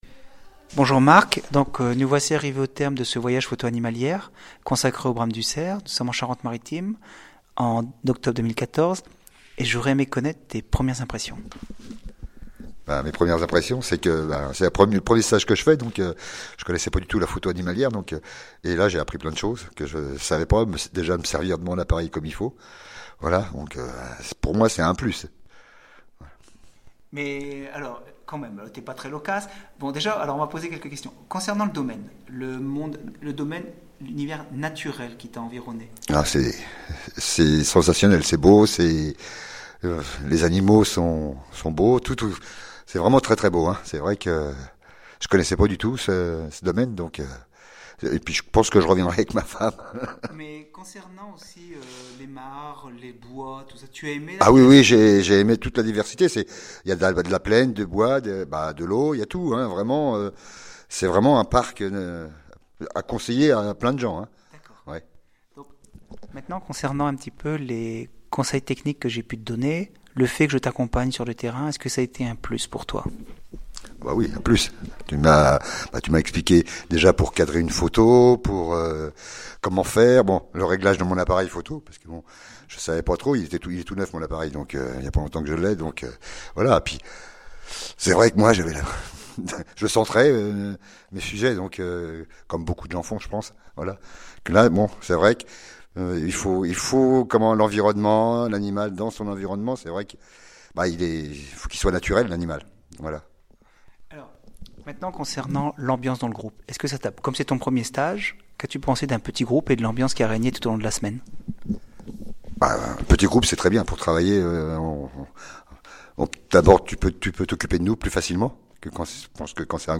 Témoignages écrits et sonores des participants